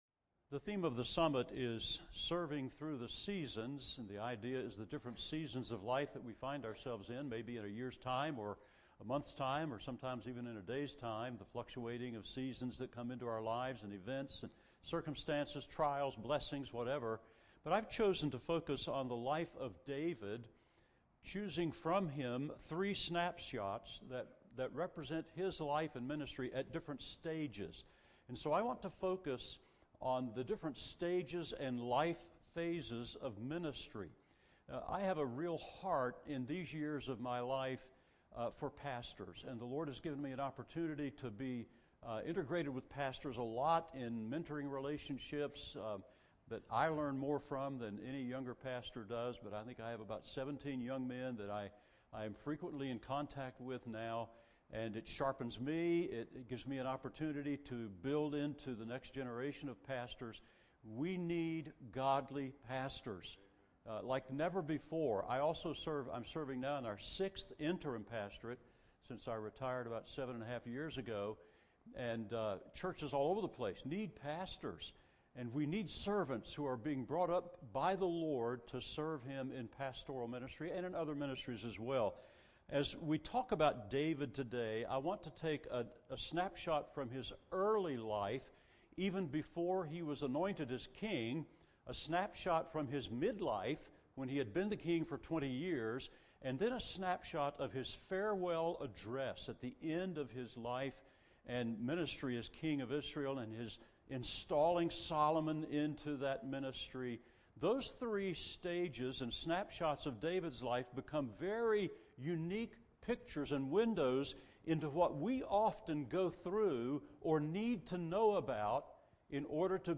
Listen to chapel message with speaker